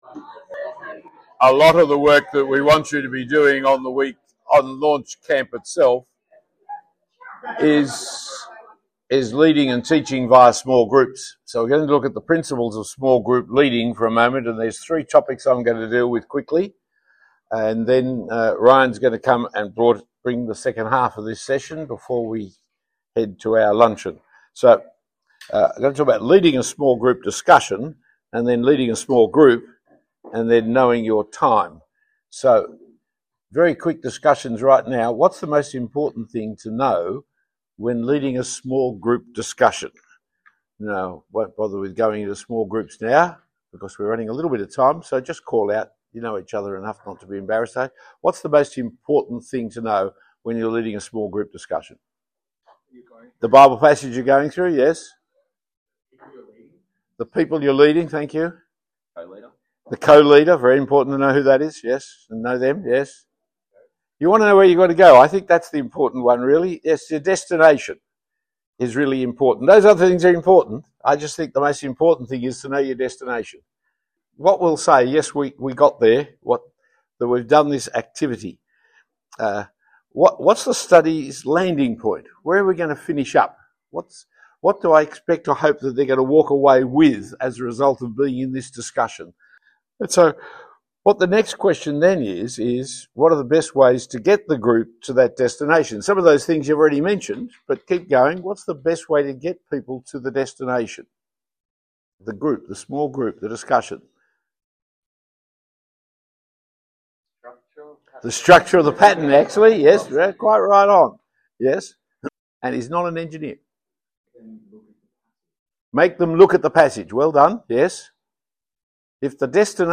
A short talk given at the Launch leaders’ training day.